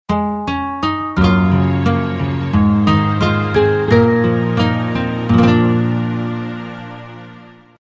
SFXXP系统开机声下载音效下载
SFX音效